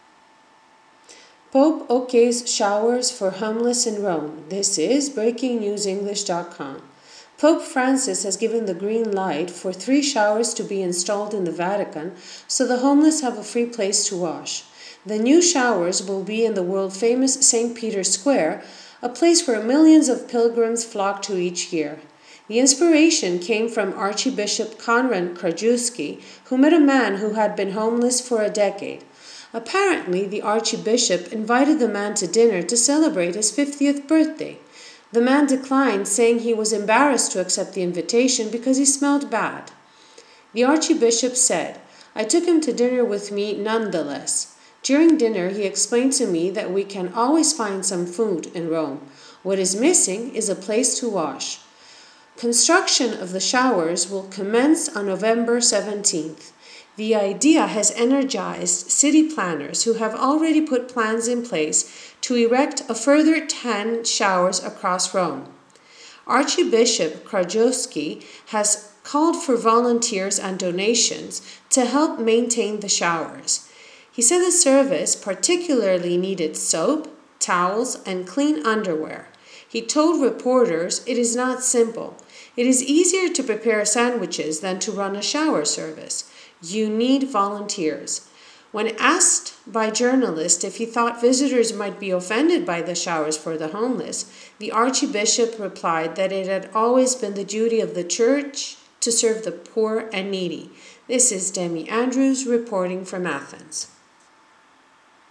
British English